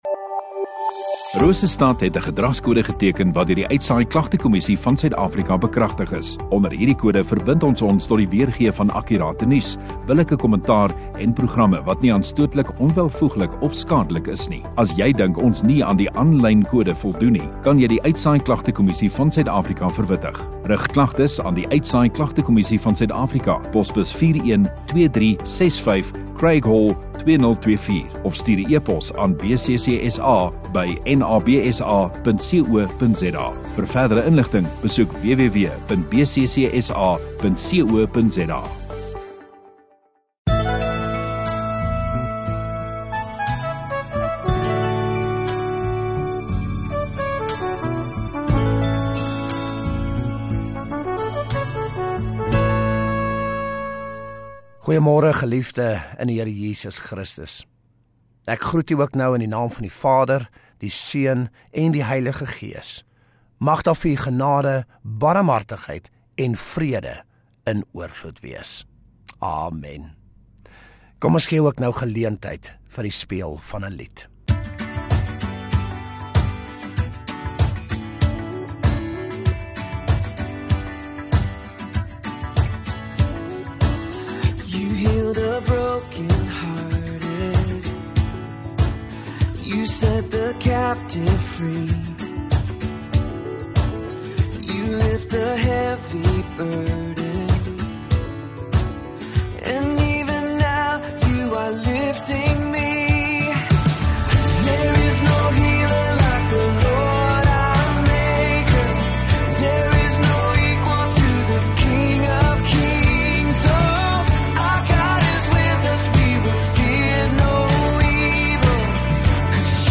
24 Sep Sondagoggend Erediens